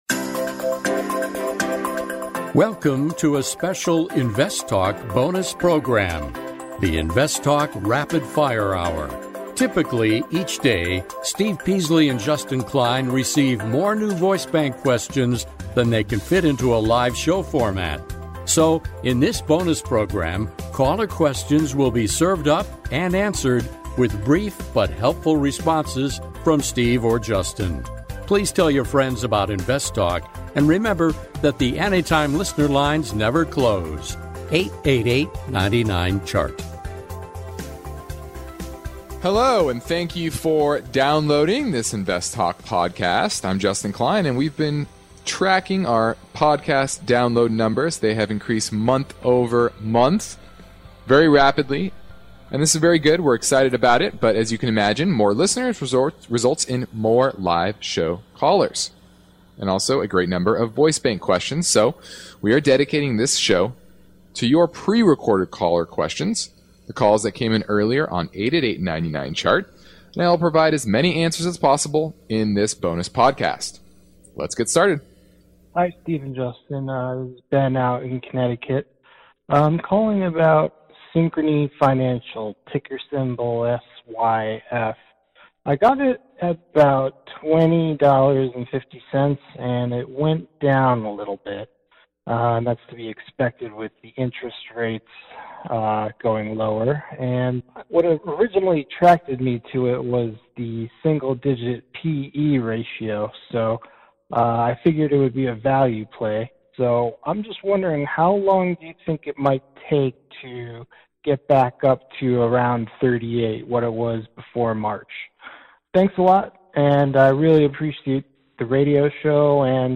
This special InvestTalk “Rapid Fire Hour” features brisk-paced answers to 30 listener questions.